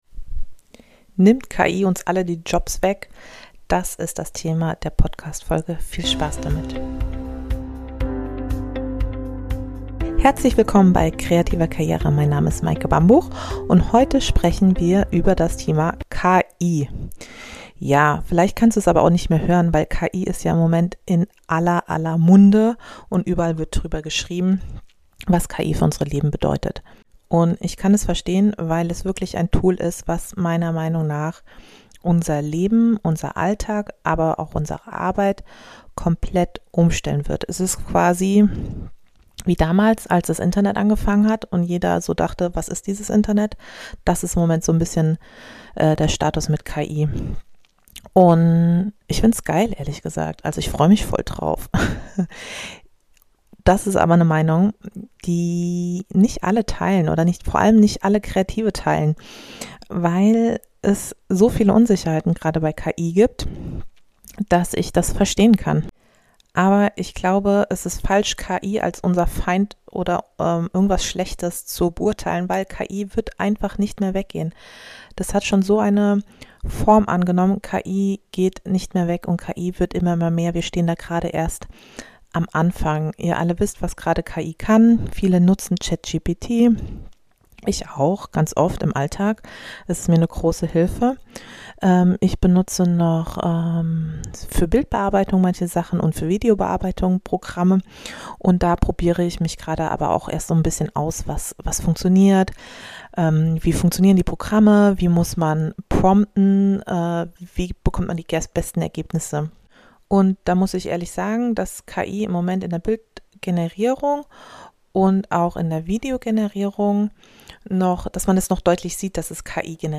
In dieser Solo-Folge teile ich meine persönliche Sicht auf